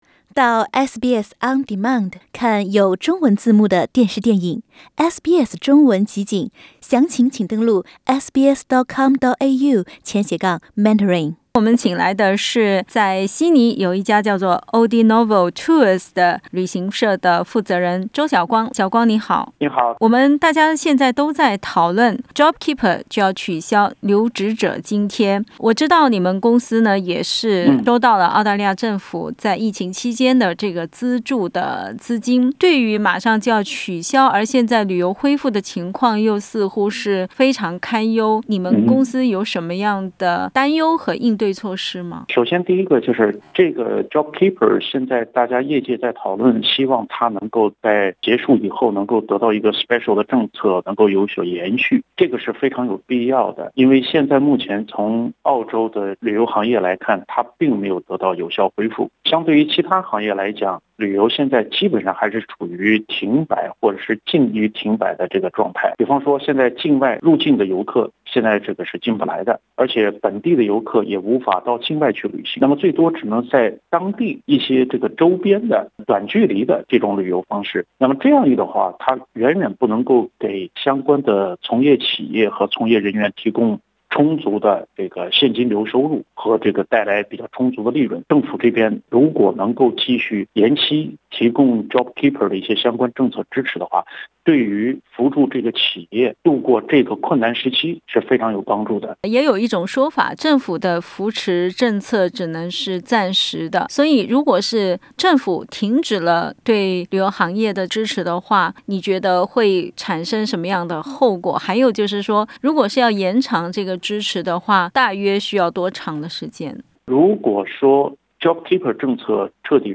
（采访内容为嘉宾观点，不代表本台立场） 澳大利亚人必须与他人保持至少1.5米的社交距离，请查看您所在州或领地的最新社交限制措施。